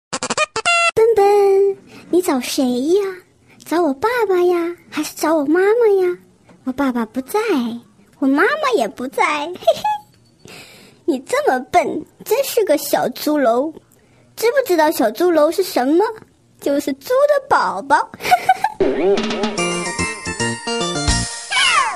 M4R铃声, MP3铃声, 搞笑铃声 117 首发日期：2018-05-14 12:46 星期一